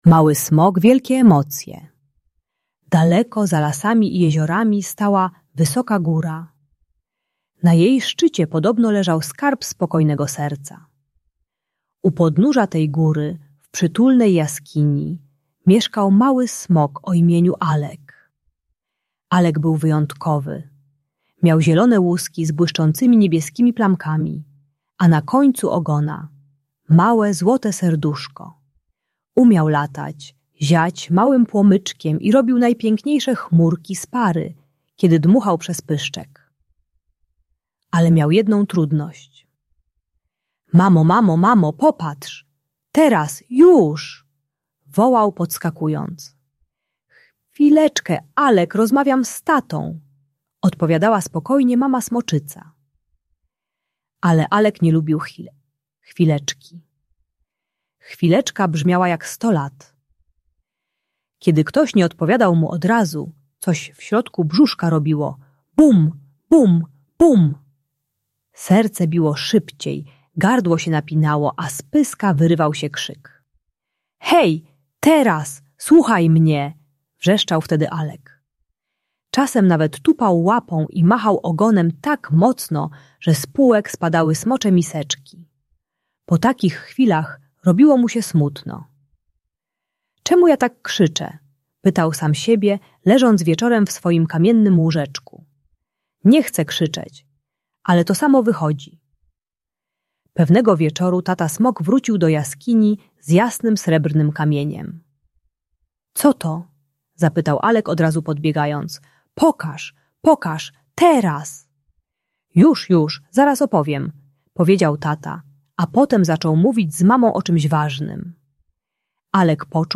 Audiobajka o złości i niecierpliwości uczy techniki spokojnego czekania, głębokiego oddychania oraz słuchania innych bez przerywania.